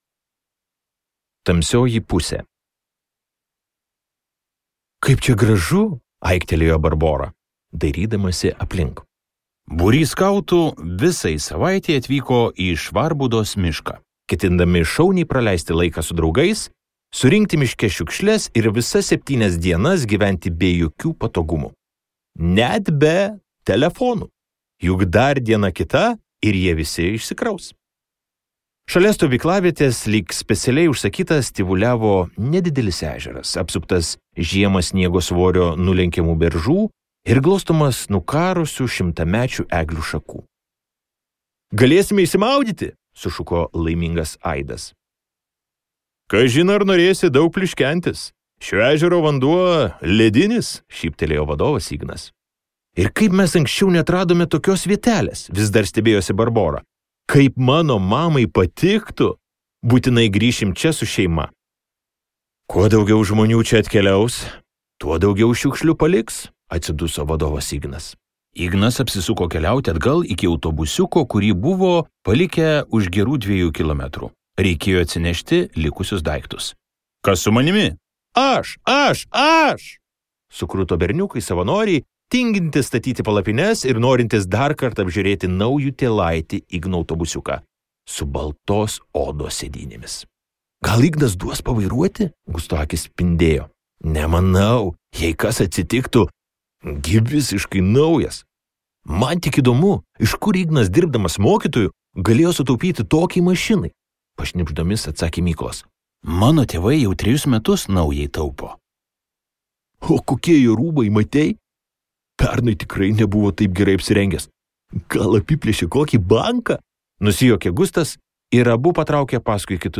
Šiurpnakčio istorijos | Audioknygos | baltos lankos